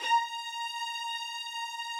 Updated string samples
strings_070.wav